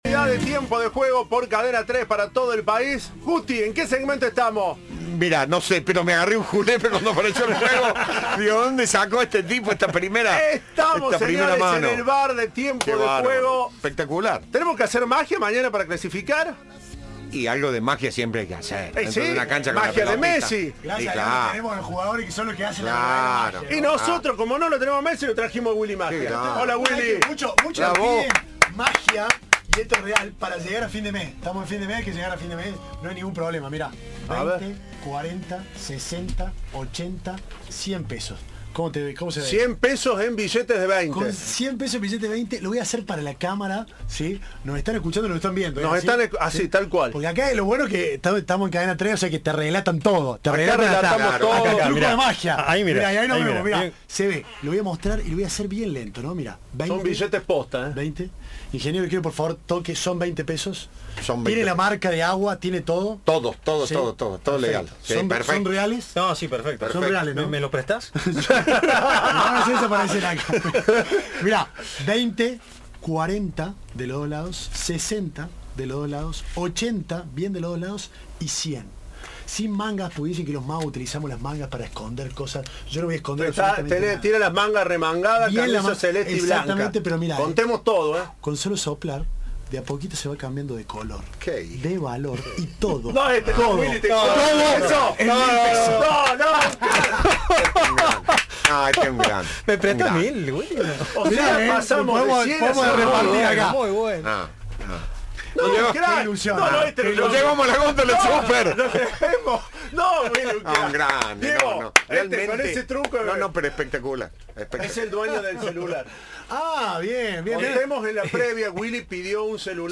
El mago visitó los estudios de Cadena 3 y habló de su vida profesional y personal, el fútbol y su fanatismo por Belgrano.